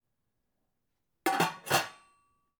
دانلود افکت صدای بستن درب قابلمه فلزی
Sample rate 16-Bit Stereo, 44.1 kHz
Looped No